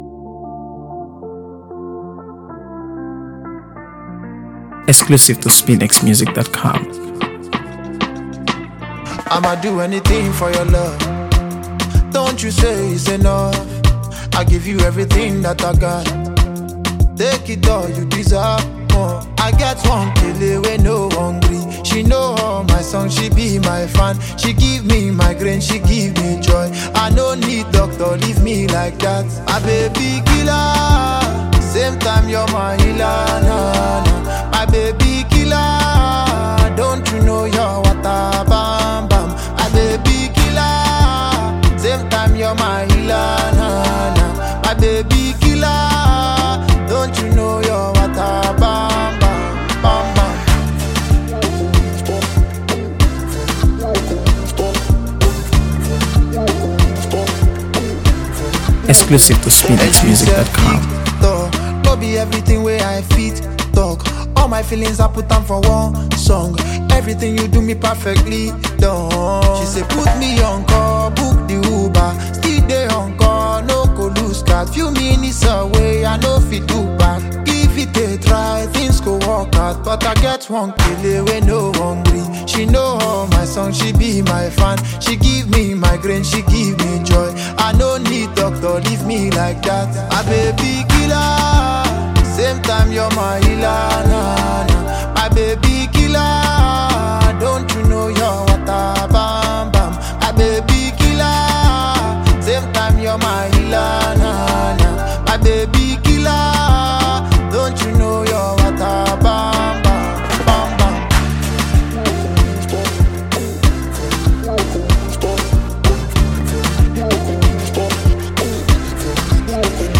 AfroBeats | AfroBeats songs
an Uplifting Anthem